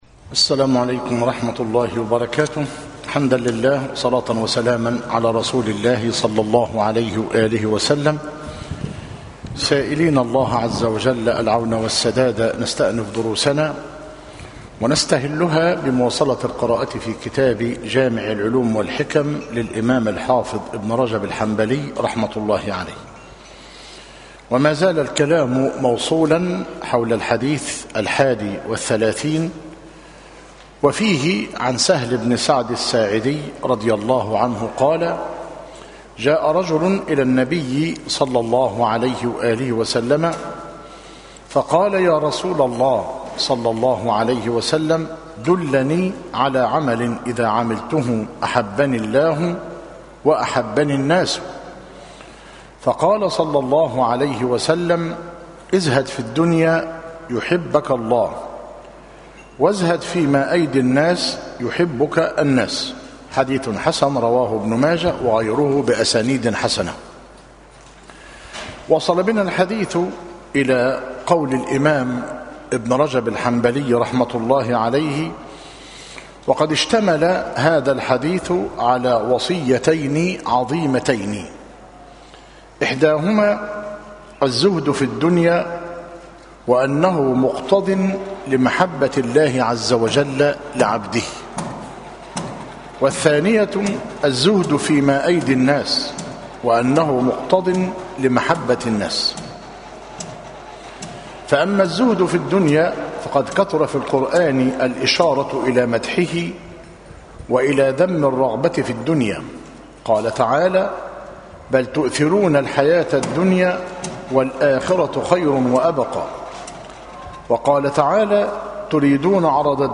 جامع العلوم والحكم- مسجد التقوى - قرية الجعافرة - مركز شبين القناطر - قليوبية - المحاضرة الحادية والتسعون - بتاريخ 19- شوال- 1437هـ الموافق 24- يوليو- 2016 م